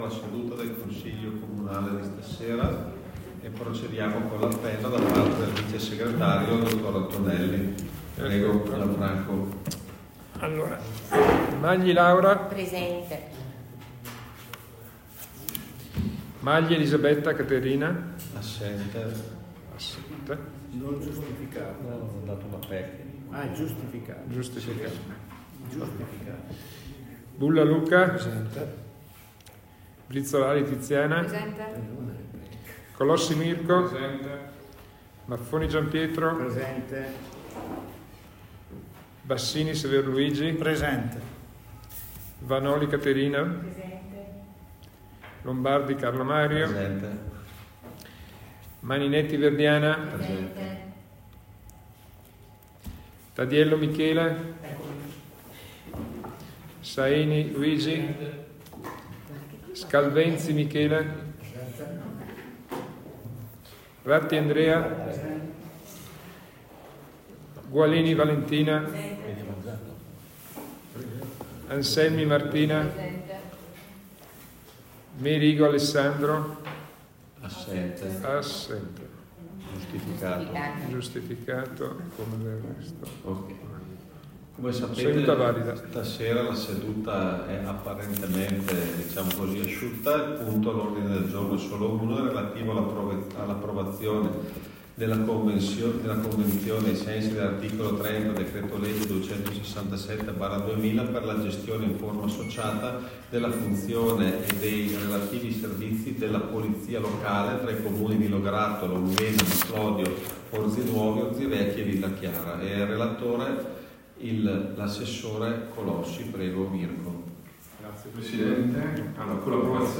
In allegato le registrazioni estratte per punti dell'ordine del giorno della seduta del Consiglio Comunale del 27 ottobre 2025.